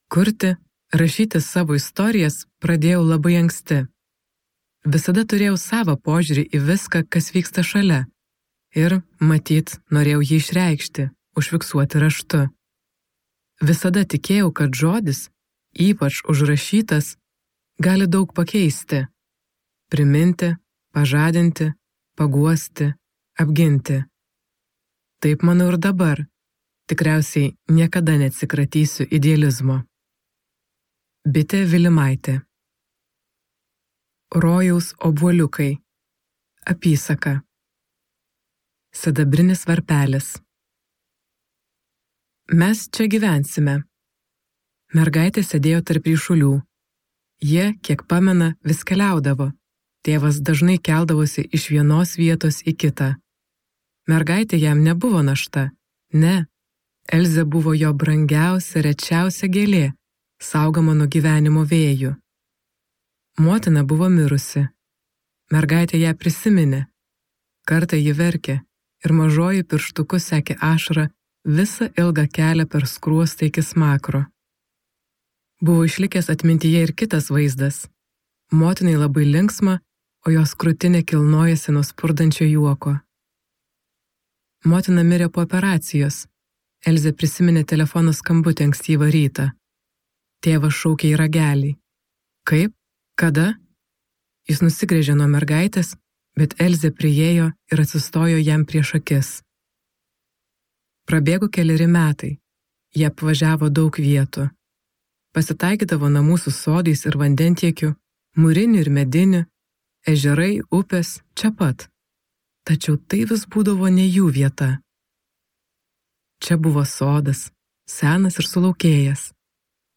Rojaus obuoliukai | Audioknygos | baltos lankos